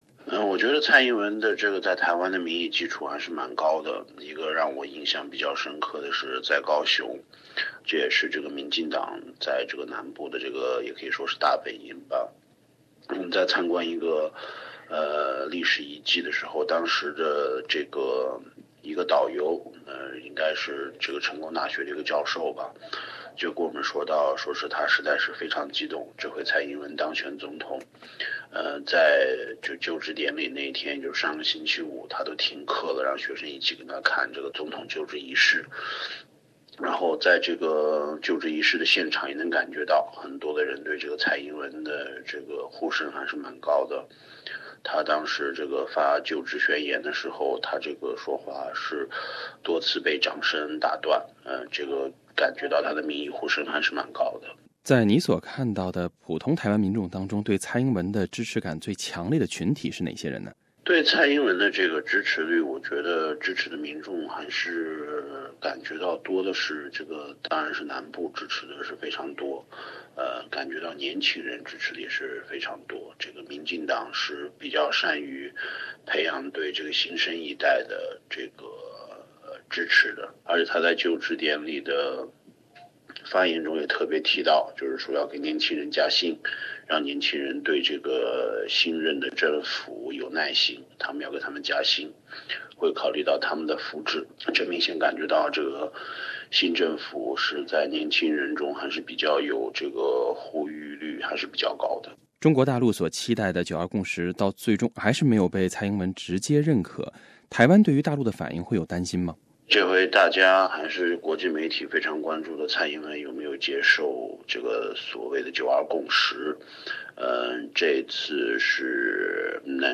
他在接受SBS采访时首先介绍了此前台湾社会的整体氛围。